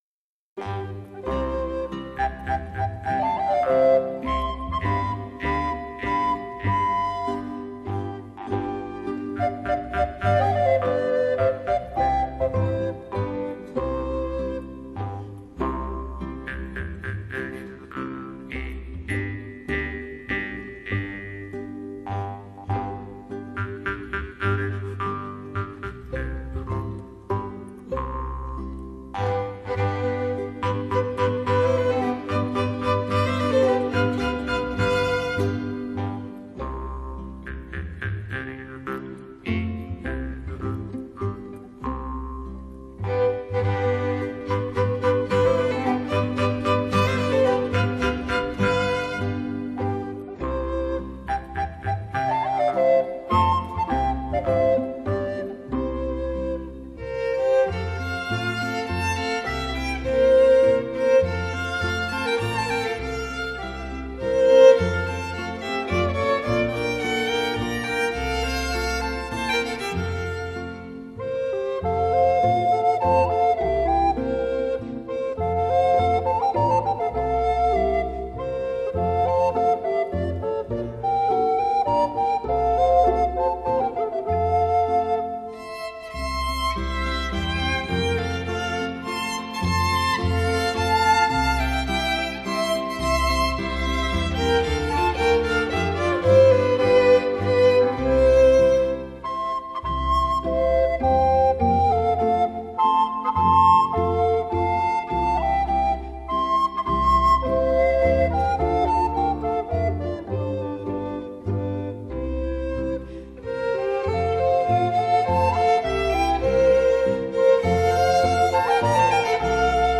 本专辑收录的作品避开了键盘音乐和通俗民谣，而力求展现具有特色的器乐 音色、音律风格以及生动的传统体验。山笛、约德尔(yodel，流行于古代瑞士和 奥地利山民之间的一种山歌，常用真假声突然互换演唱)、 齐特琴的表演非常精 彩，而用萨克斯演绎出的模仿约德尔的音色，也算是对传统的一种带有调侃性质 的发展。